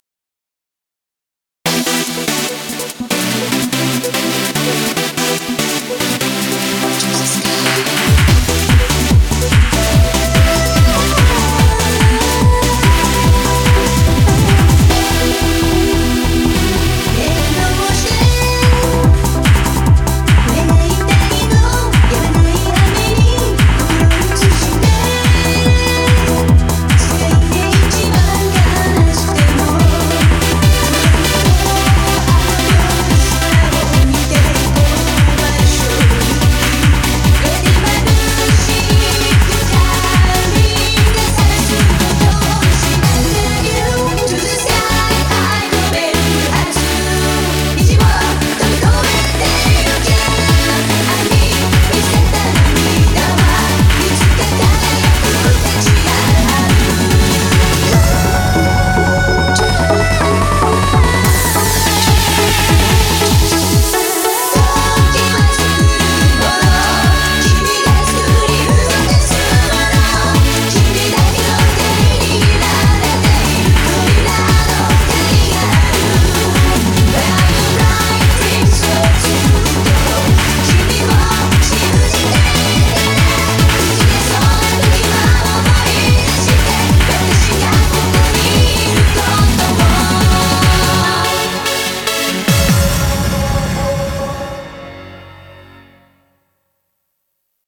BPM145
Audio QualityMusic Cut